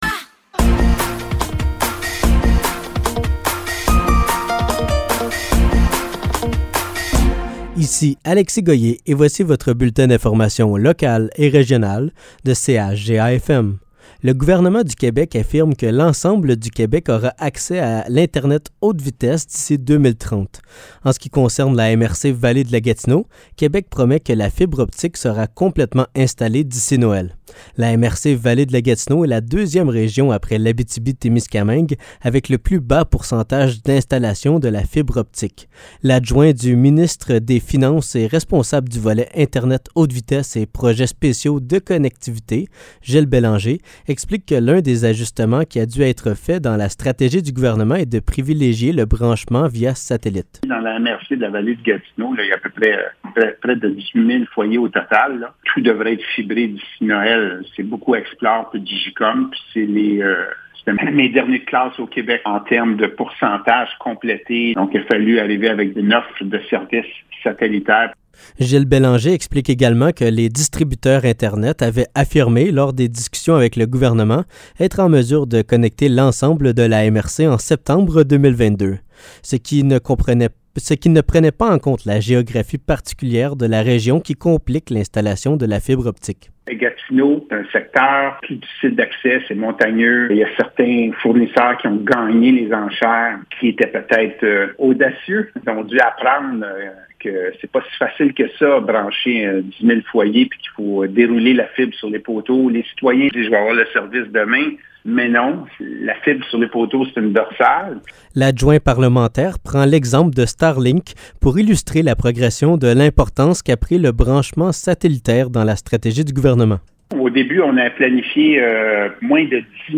Nouvelles locales - 6 septembre 2023 - 15 h